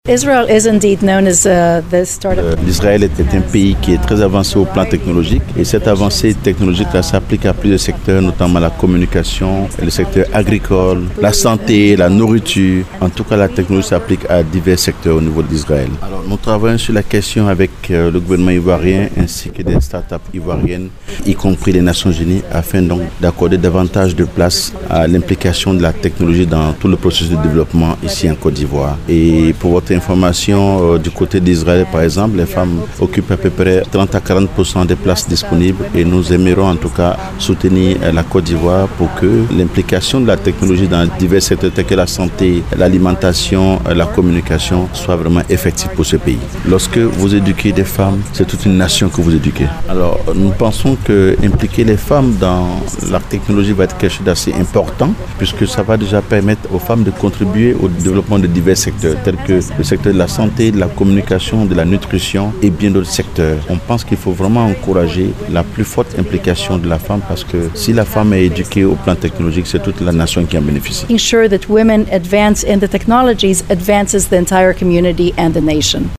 Pour réduire cet écart, Israël s’engage à accompagner la Côte d’Ivoire en offrant des opportunités pour l’amélioration de la vie des femmes et des filles . Ecoutons les explications de  l’Ambassadeur d’Israël en Côte d’Ivoire dans des propos traduits de l’anglais  en français.